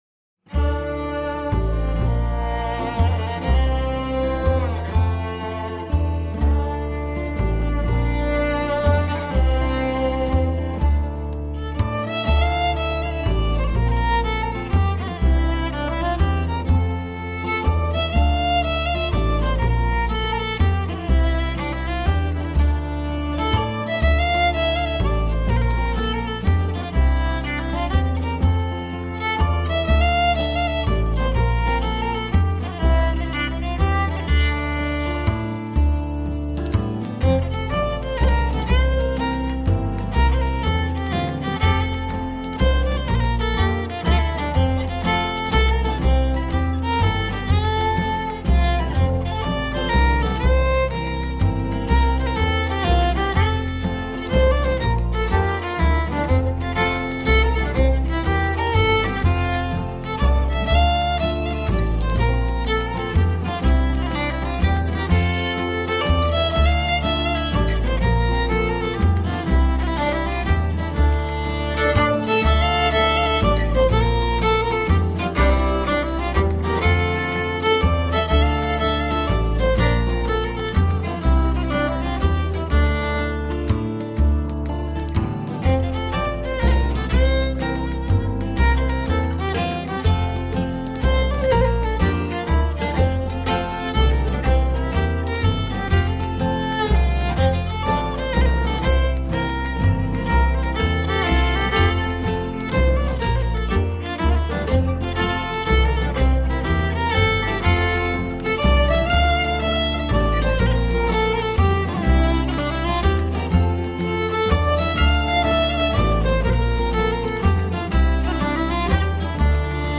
Musique et cuisine Québécoise
Accordéon / Accordion
Guitar - Banjo - Spoons - Foot tapping
Bodhran - Washboard - Bones